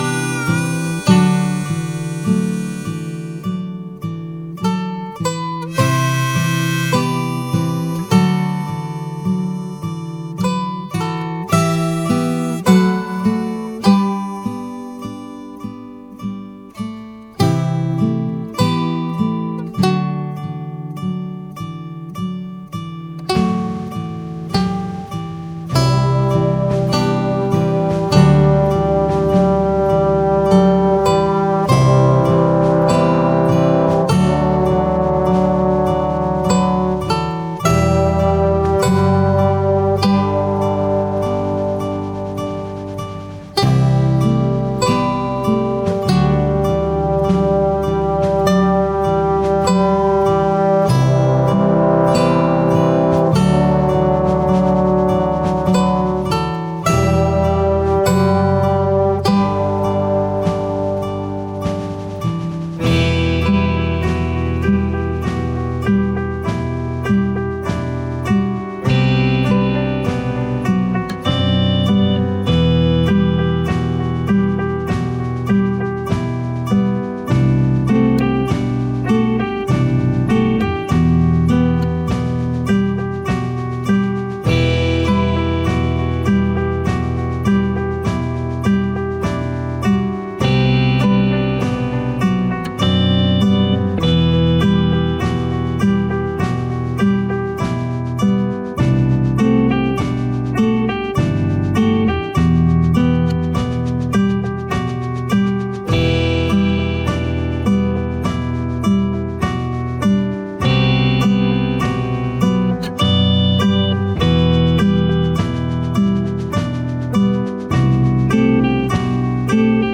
Free Post-Rock Background Music (No Copyright)